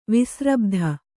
♪ visrabdha